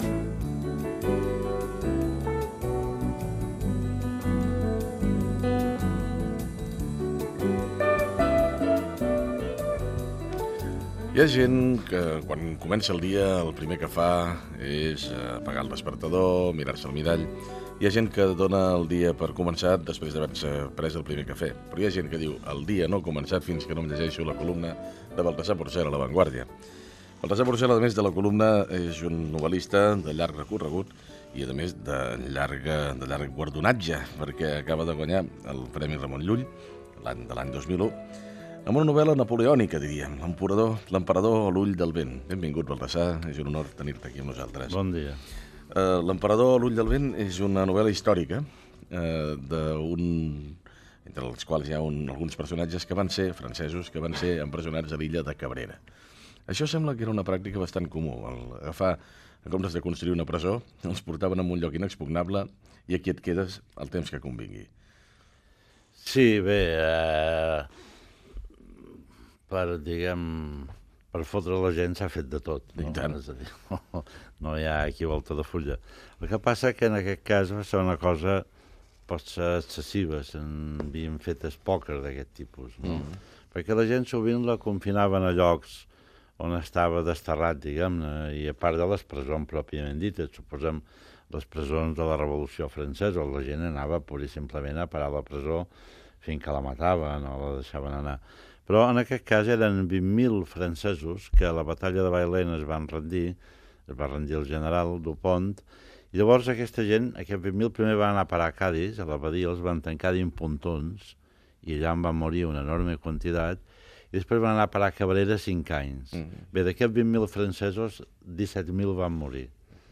Perfil biogràfic i fragment d'una entrevista a l'escriptor Baltasar Porcel.
Info-entreteniment